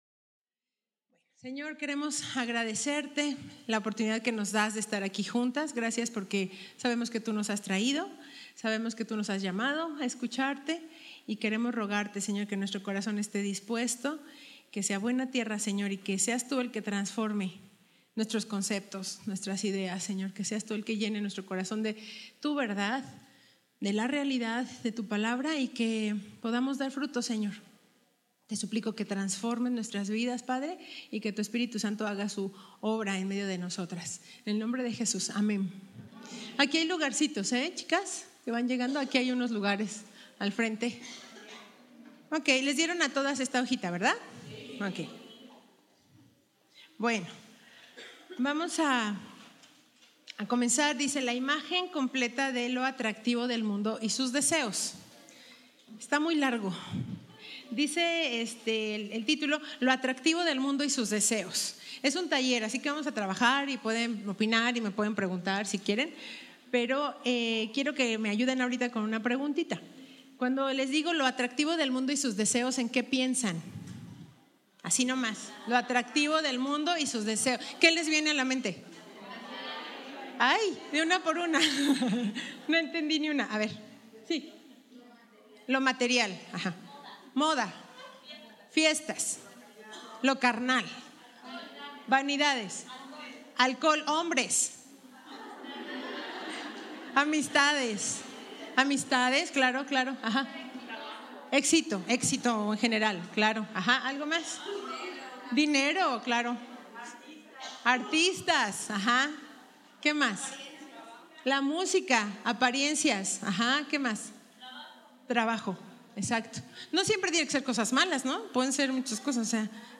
Retiro 2018